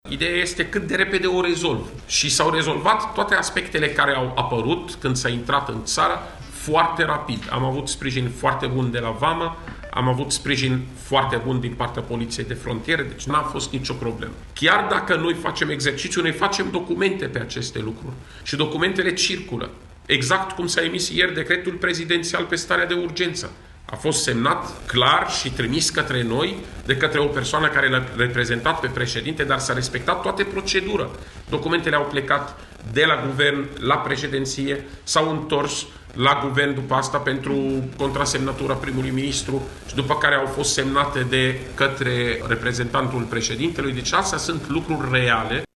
Secretarul de stat al Departamentului pentru situații de Urgență, Raed Arafat a declarat azi că întârzieri de reacții nu au existat, ci doar aspecte de reglat cu documentele oficiale: